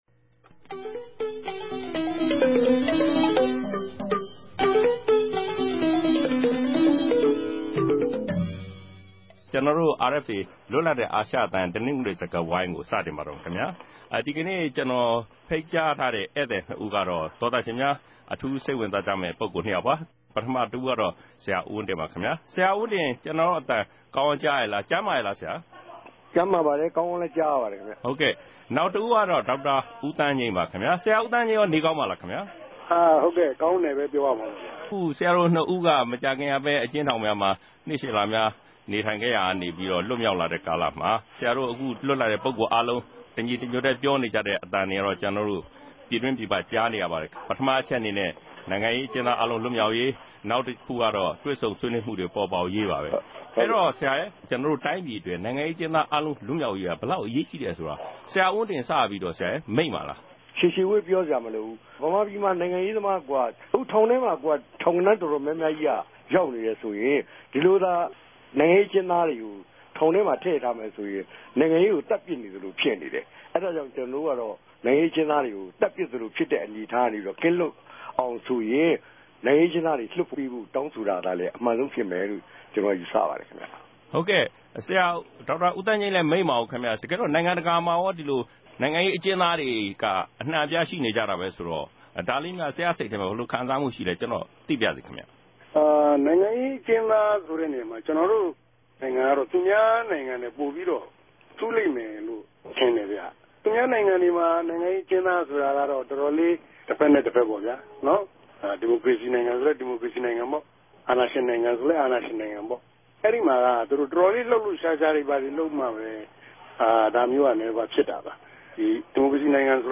ဒီအပတ်တနဂဿေိံြ စကားဝိုင်းမြာ မုကာခင်မြာ ထောင်ကလြတ်ေူမာက်လာတဲ့ ဆရာဦးဝင်းတင်နဲႚ ဒေၝက်တာသန်း္ဘငိမ်းတိုႚ ဆြေးေိံြးထားုကပၝတယ်။